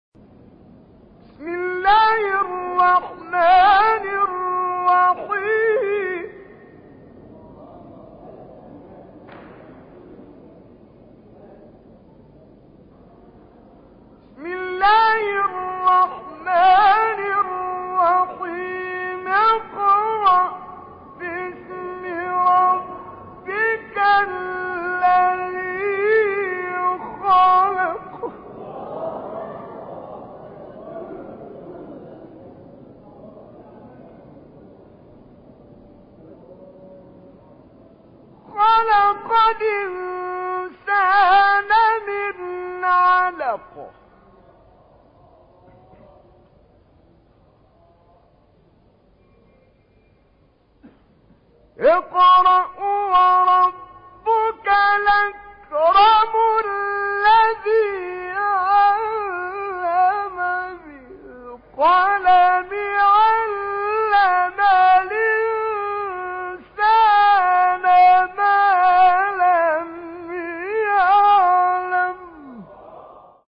گروه فعالیت‌های قرآنی: مقاطع زیبا و شنیدی از قاریان مصری و ایرانی که در کانال‌ها و گروه‌های قرآنی تلگرام منتشر شده است، ارائه می‌شود.
تلاوت ترکیبی سه گاه و عجم سوره مبارکه علق از کامل یوسف که از آیه دوم وارد مقام عجم می‌شود